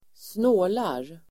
Uttal: [²sn'å:lar]